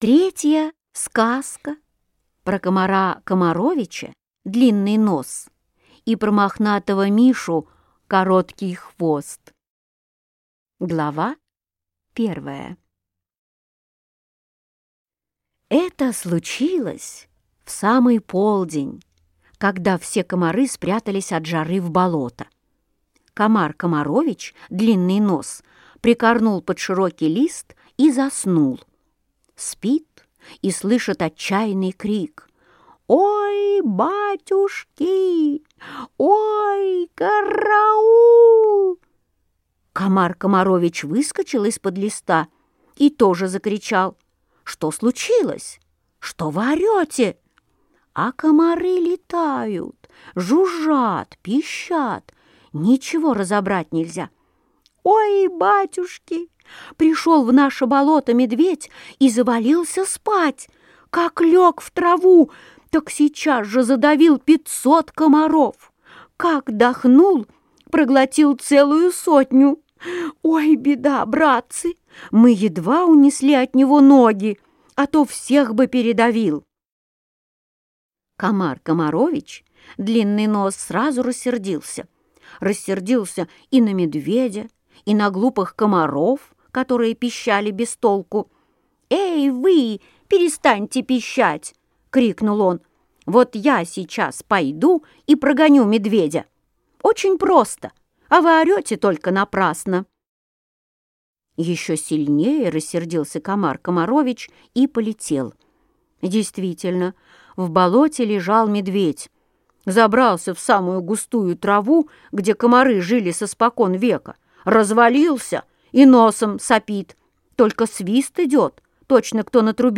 Аудиокнига Аленушкины сказки | Библиотека аудиокниг